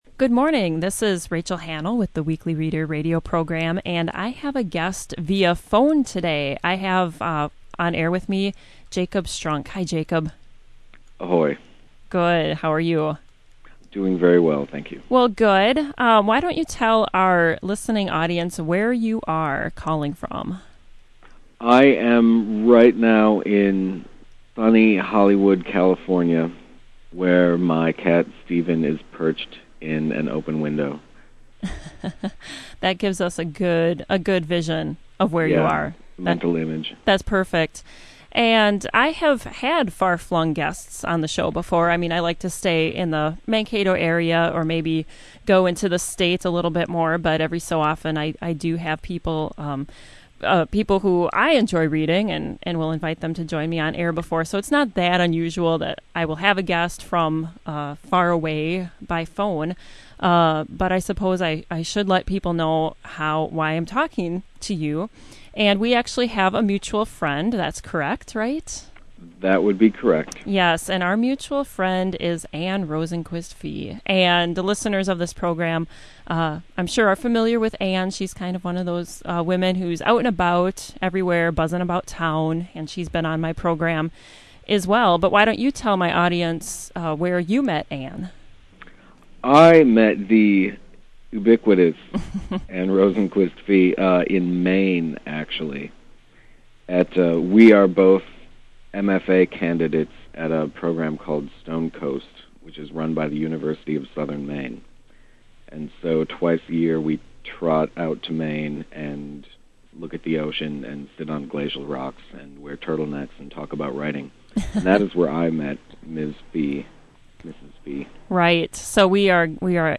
Interview and readings.